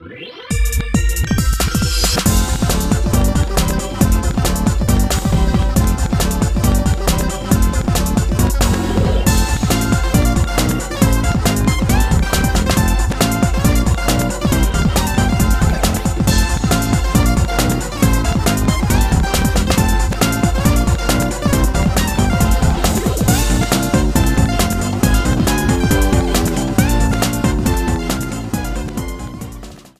Sound clip of an arrangement